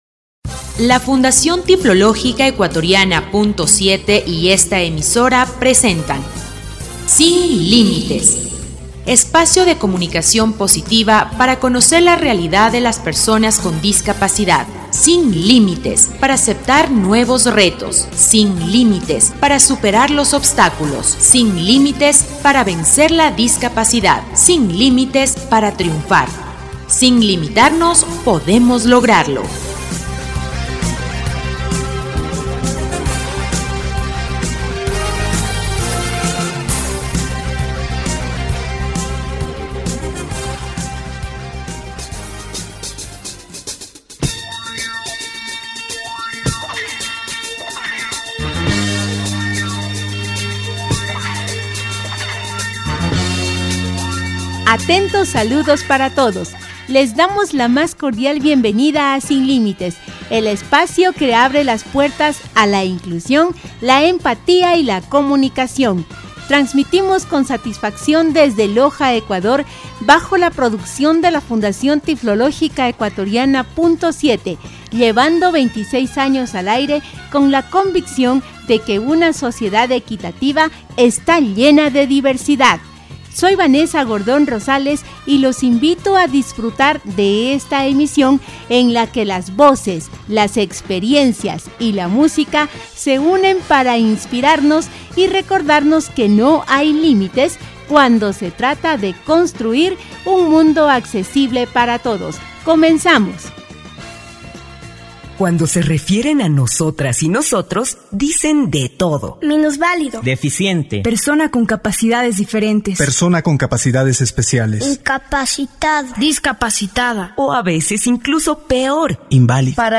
Programa radial «Sin Límites» 1350